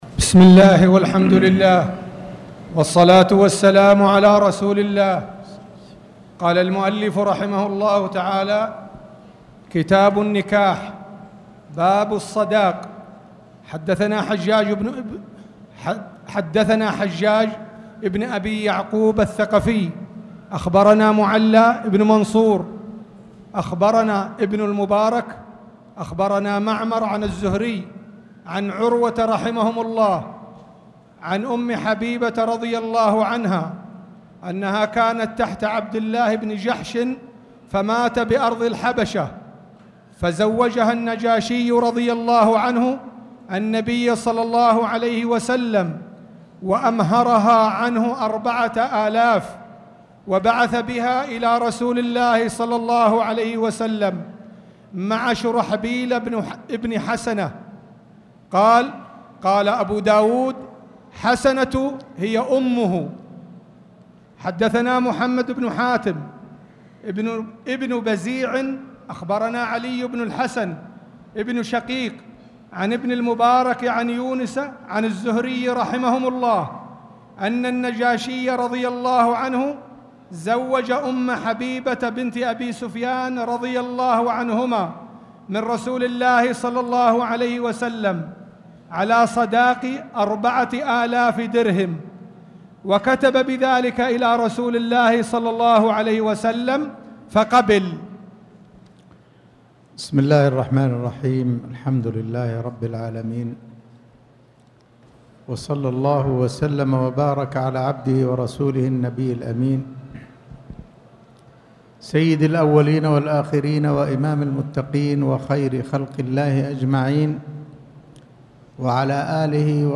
تاريخ النشر ٢٩ محرم ١٤٣٨ المكان: المسجد الحرام الشيخ